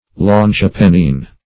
Meaning of longipennine. longipennine synonyms, pronunciation, spelling and more from Free Dictionary.
Search Result for " longipennine" : The Collaborative International Dictionary of English v.0.48: Longipennine \Lon`gi*pen"nine\, a. (Zool.)